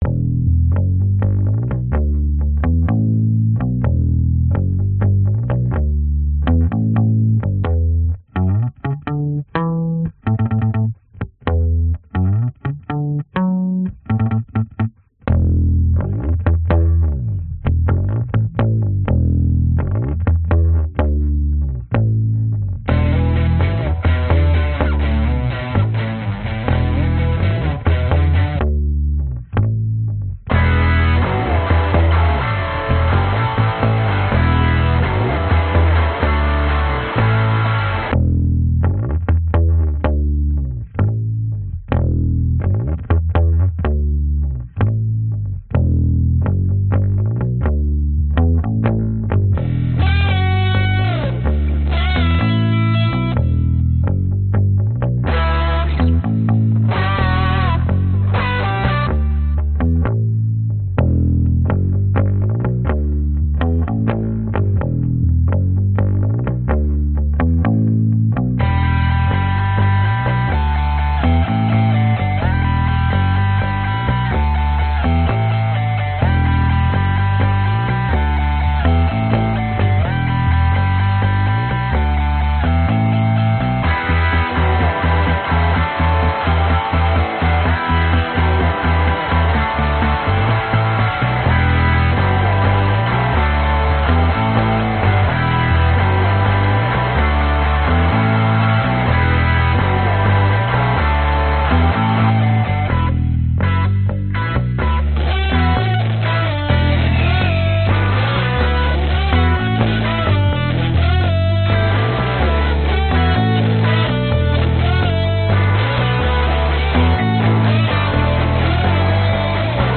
描述：只是一个小小的音乐叮当声寻找宝藏！
标签： 卡通 发现 赏金 电影 音乐 游戏 电影配乐 海盗 自由 主题 查找 电影 黄金 soundesign 滑稽
声道立体声